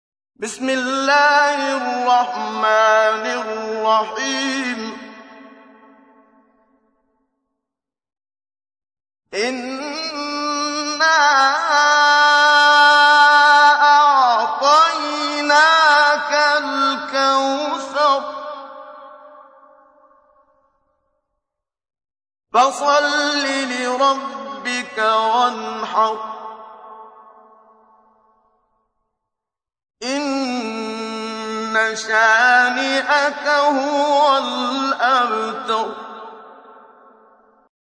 تحميل : 108. سورة الكوثر / القارئ محمد صديق المنشاوي / القرآن الكريم / موقع يا حسين